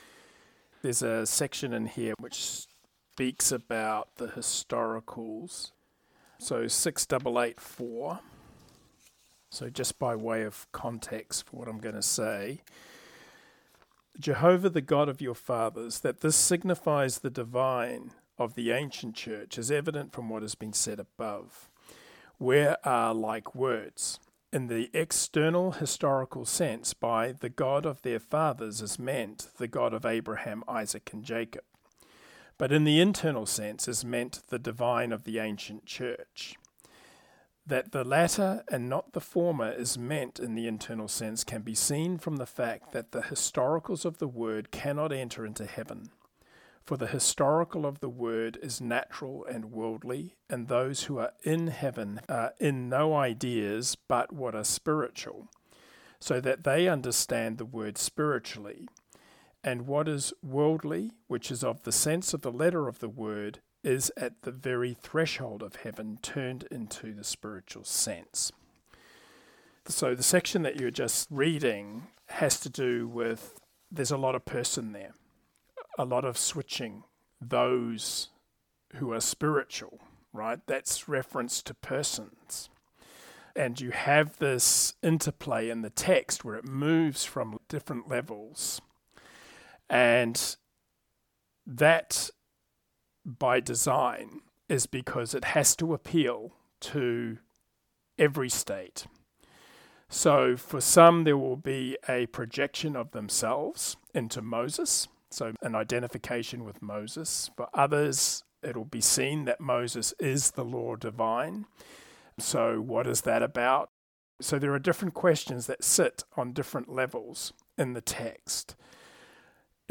Third Round posts are short audio clips taken from Round 3 comments offered in the online Logopraxis Life Group meetings. The aim is to keep the focus on understanding the Text in terms of its application to the inner life along with reinforcing any key LP principles that have been highlighted in the exchanges.